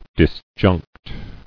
[dis·junct]